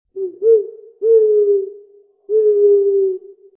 Download Owl sound effect for free.
Owl